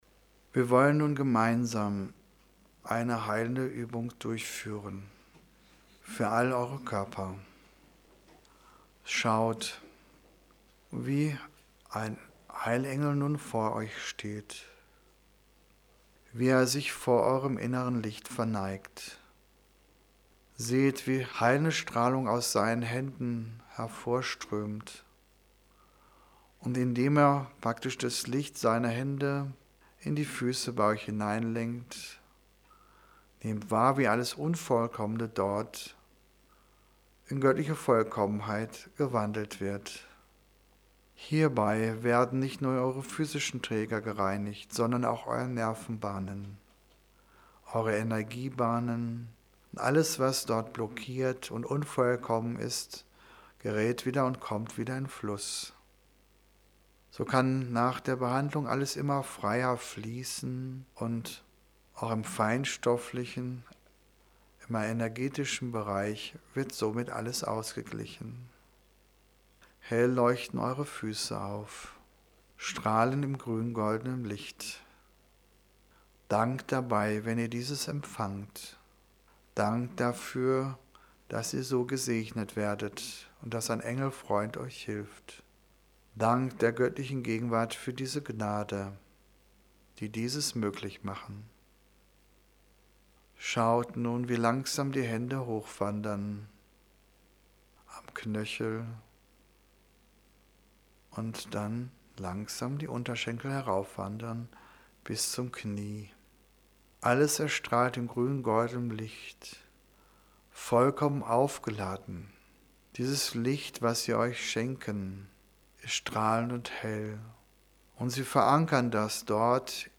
Heilende_Uebung_15m44s.mp3